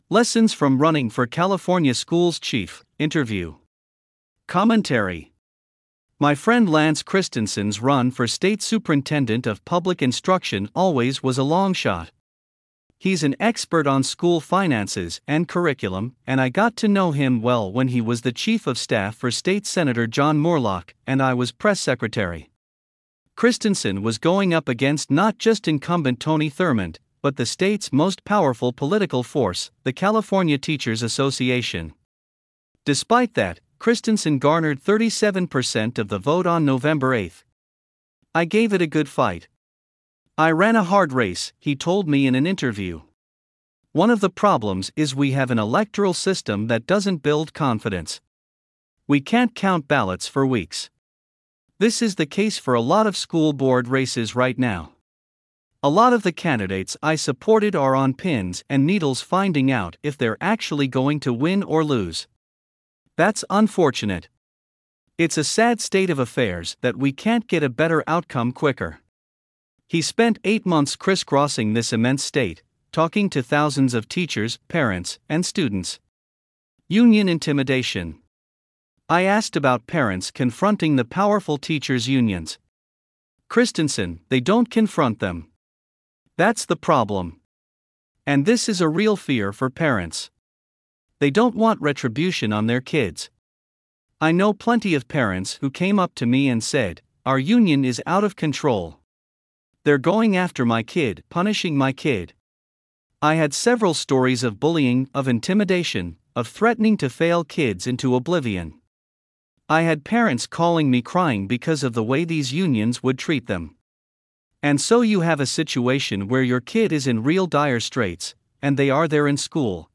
Lessons From Running for California Schools Chief: Interview | California Insider